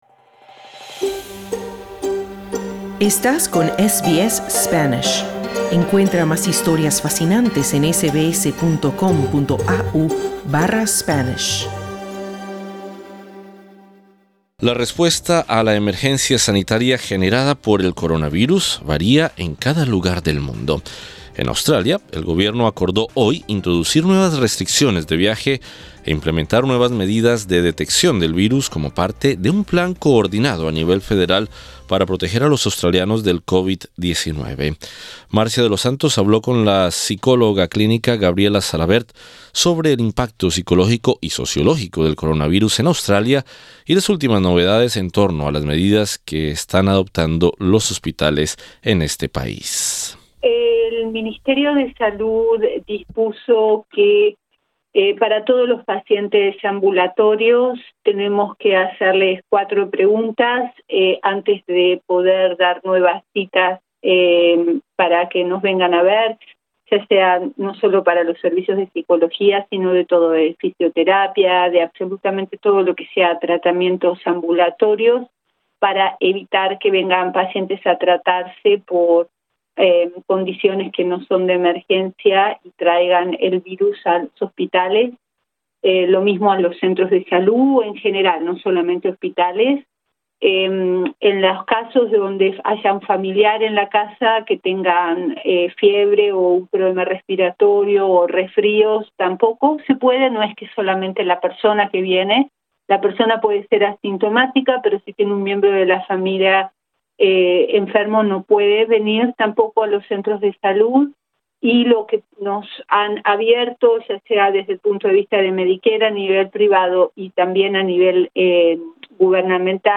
El pánico generado por el coronavirus en Australia es para muchos injustificado. Pero hay personas que por sentirse debilitadas psicológicamente recurren a medidas exageradas. En entrevista con SBS Spanish